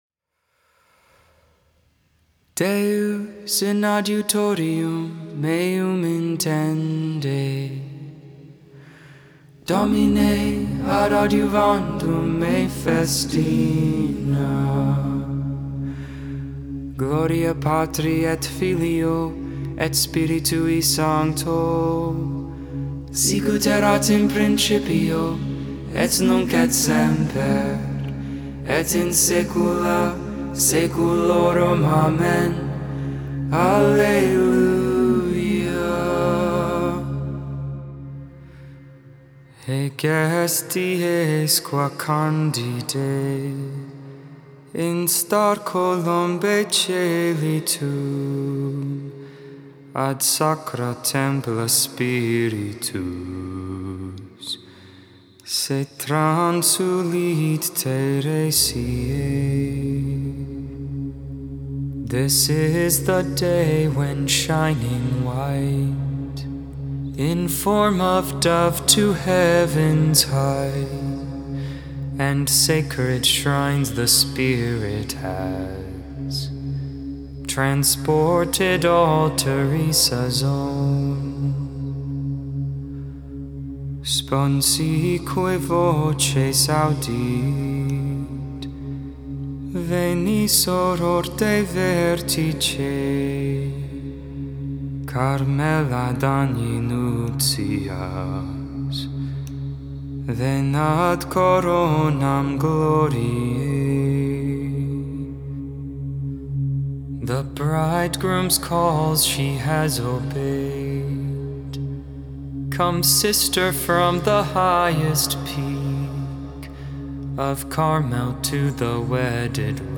10.15.21 Vespers, Friday Evening Prayer